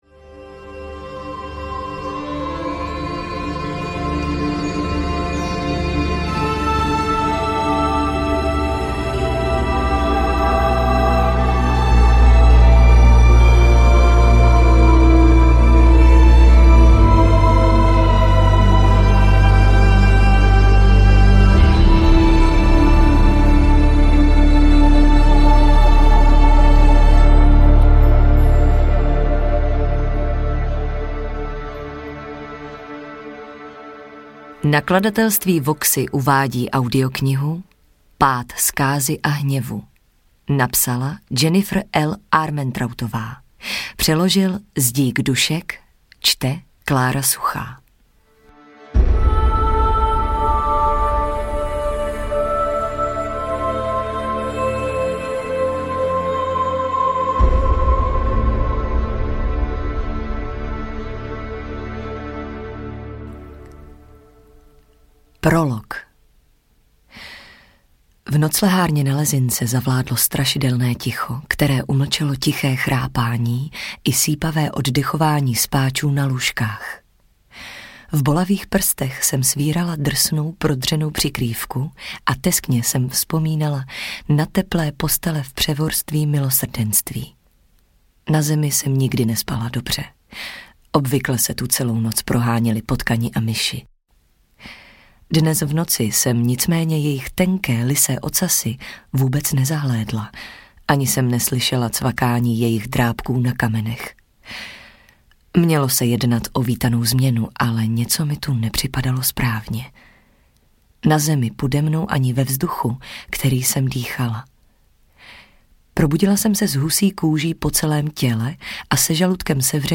Pád zkázy a hněvu audiokniha
Ukázka z knihy